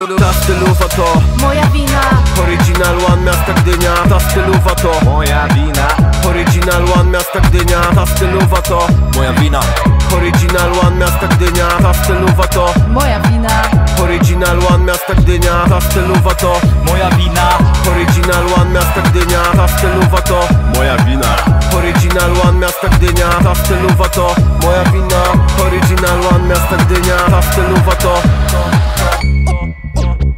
Kategoria Rap/Hip Hop